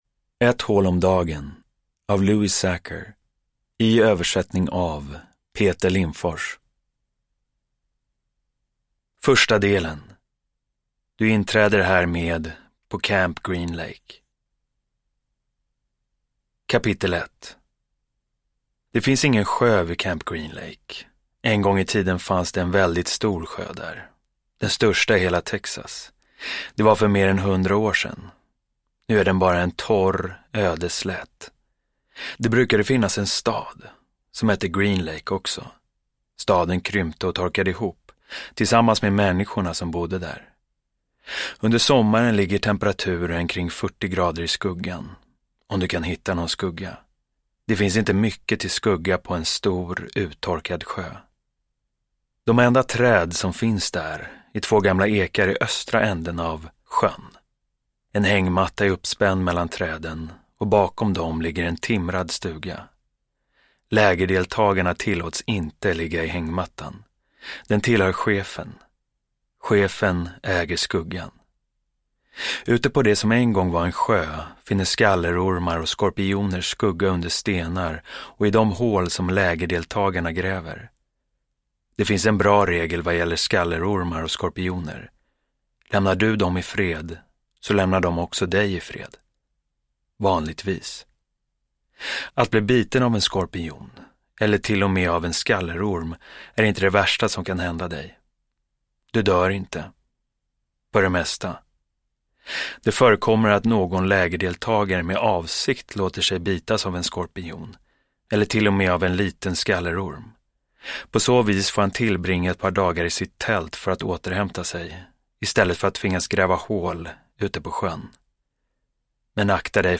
Ett hål om dagen – Ljudbok